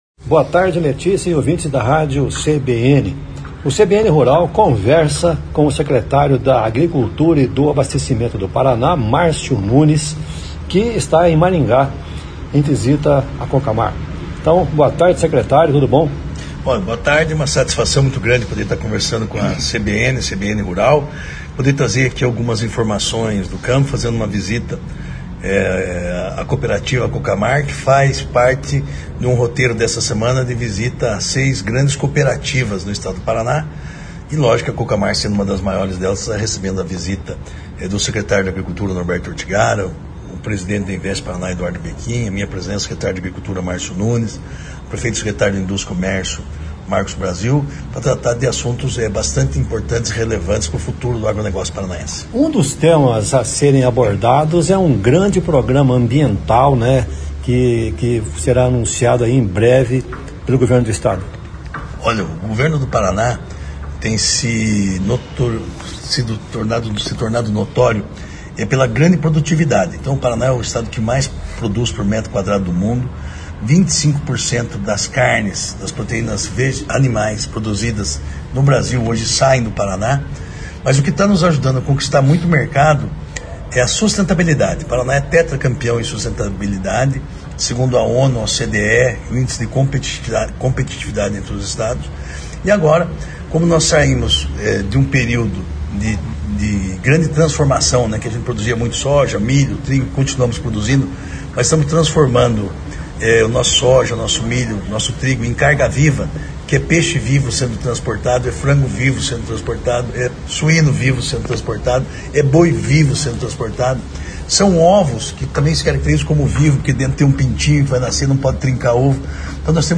“São investimentos importantes para a chegada de insumos e saída de produtos, como também para garantir qualidade de vida aos moradores das regiões rurais, que também terão melhoria no acesso a escolas, hospitais, turismo e melhor comodidade no dia a dia”, disse o secretário da Agricultura e do Abastecimento, Marcio Nunes, em entrevista ao CBN Rural.